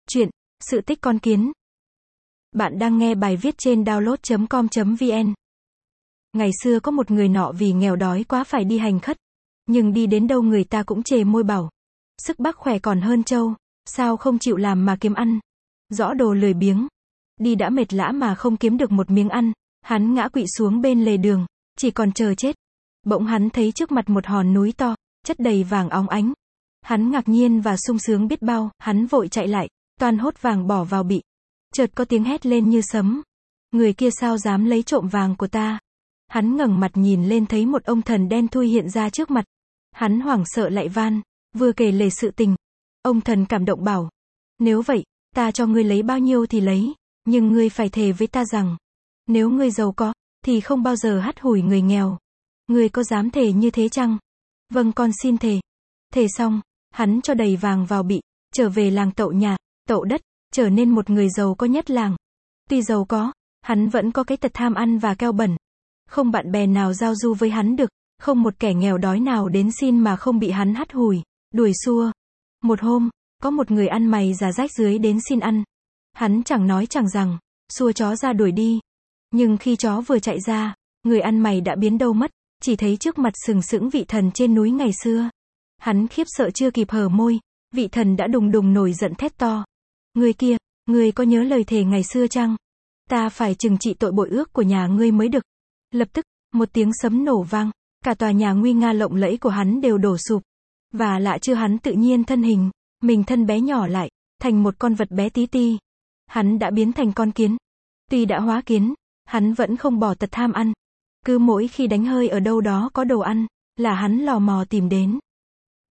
Sách nói | Sự tích con kiến